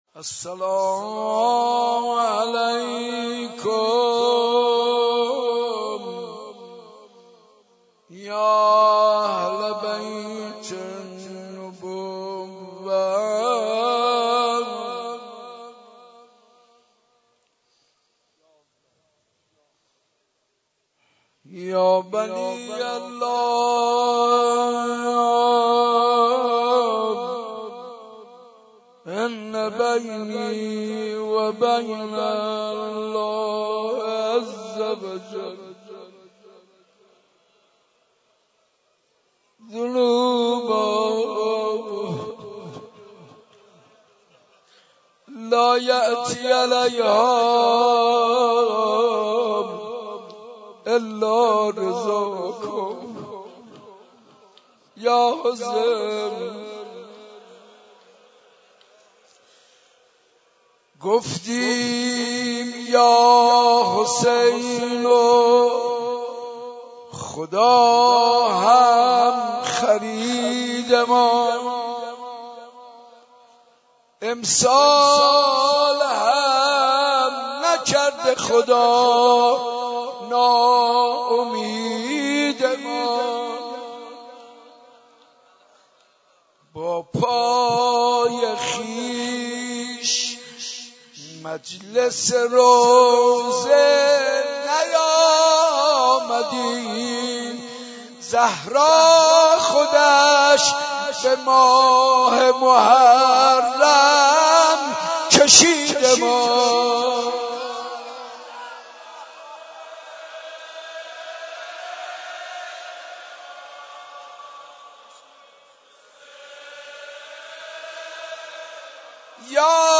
شب پنجم محرم95/مسجد ارک تهران